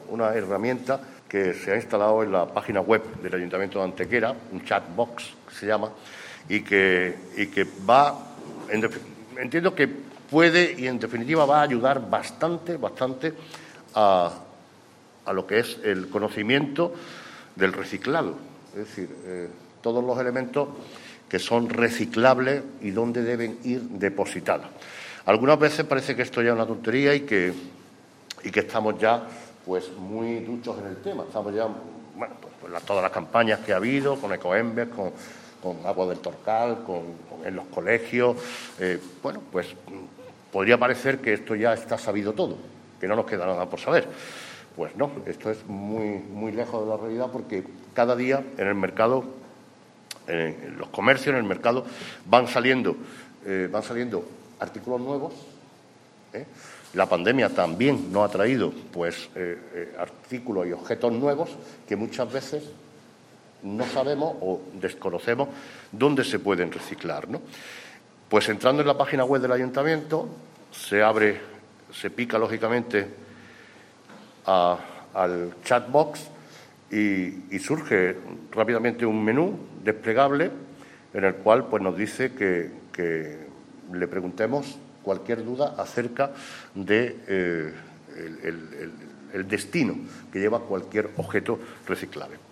ENLACE A VÍDEO DE LA RUEDA DE PRENSA EN YOUTUBE
Cortes de voz